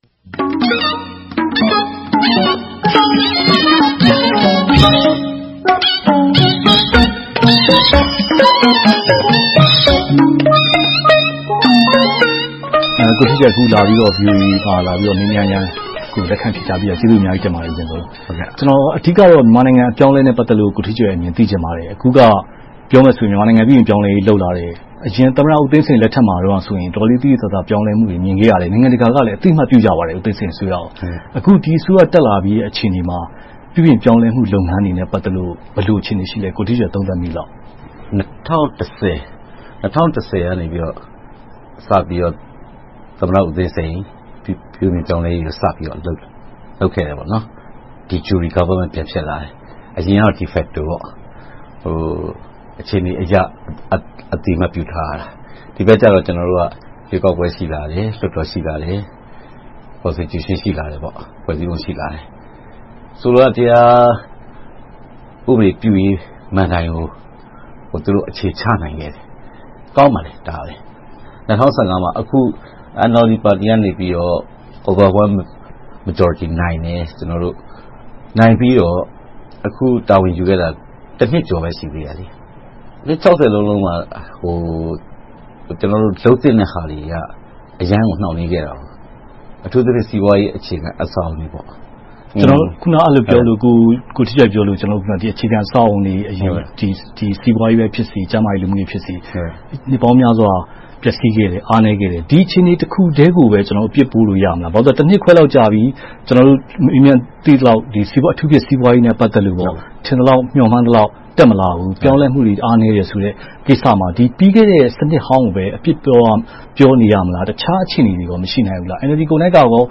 ဝါရှင်တန်ဒီစီ VOA Studio မှာ